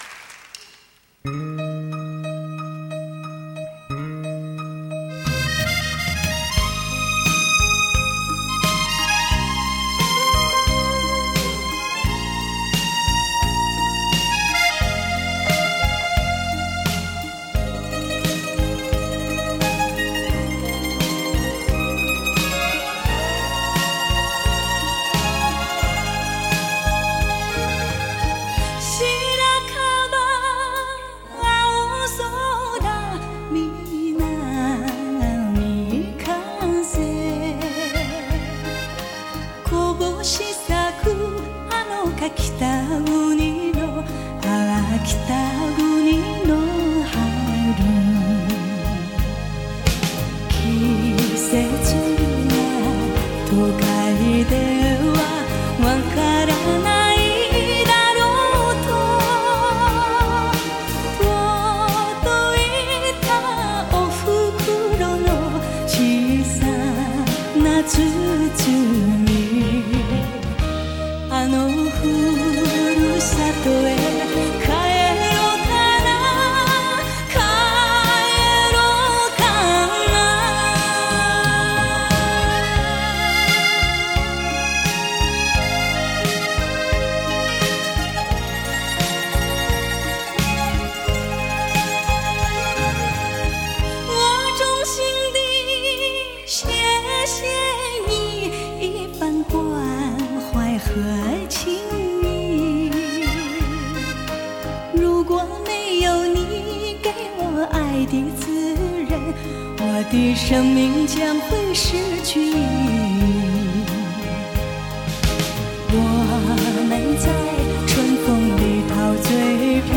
1985日本东京NHK演唱会 2CD 完全版
这场演唱会的录音水准，可以说是当年的最高标准，甚至超越了许多现在发行的现场录音质量。
而伴奏、和音、观众的现场声音也都独立录制。
乐队的配器也极其出色，充分展示了日本流行音乐的高超水平。